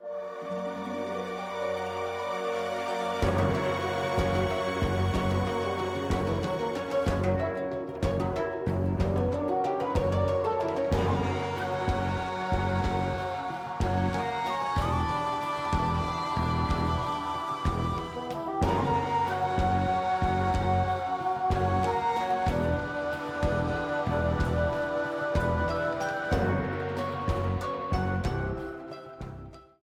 A theme
Ripped from the game
clipped to 30 seconds and applied fade-out